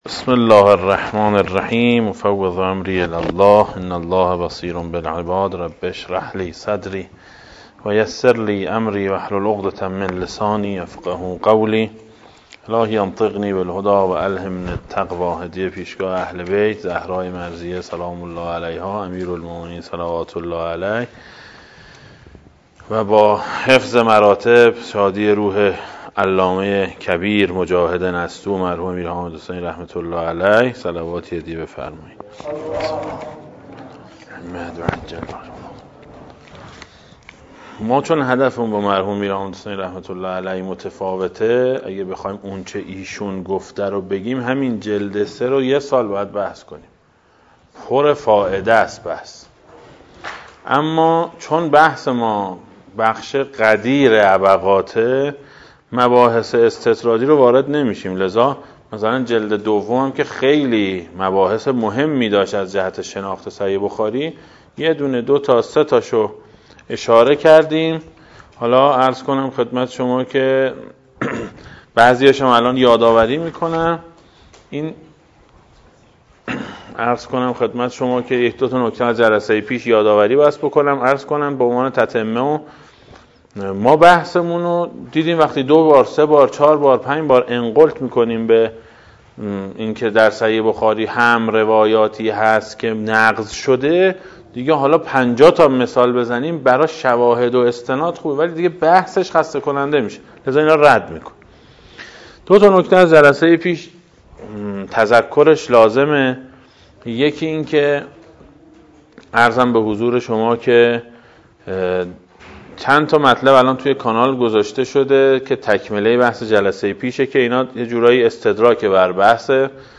در مدرس پژوهش حوزه علمیه امام خمینی (ره) تهران برگزار گردید که مشروح این کلاس تقدیم می شود.